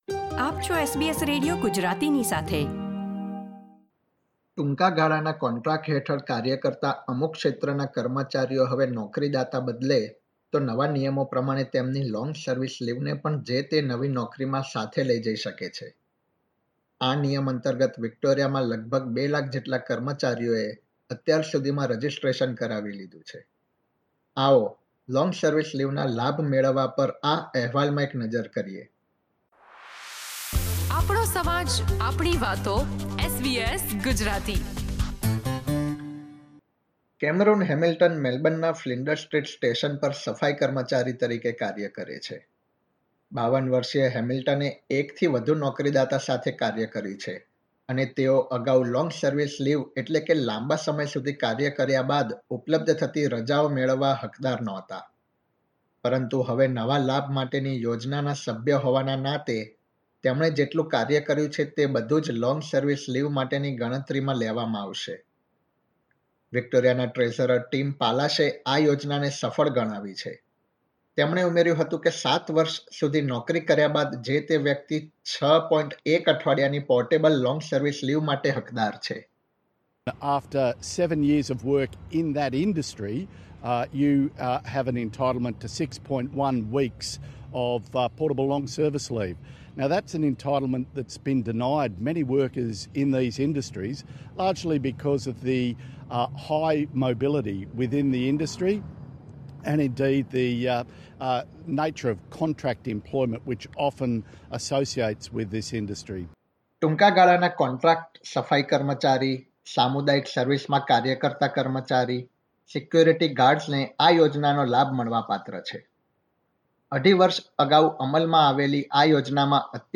ટૂંકાગાળાના કોન્ટ્રાક્ટ હેઠળ કાર્ય કરતા અમુક ક્ષેત્રના કર્મચારીઓ હવે નોકરીદાતા બદલે તો નવા નિયમો પ્રમાણે તેમની લોંગ સર્વિસ લીવને પણ જે – તે નવી નોકરીમાં લઇ જઇ શકે છે. આ નિયમ અંતર્ગત, વિક્ટોરીયામાં લગભગ 2 લાખ જેટલા કર્મચારીઓએ રજીસ્ટ્રેશન કરાવી લીધું છે. આવો, લોંગ સર્વિસ લીવના લાભ પર અહેવાલમાં નજર કરીએ.